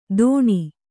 ♪ dōṇi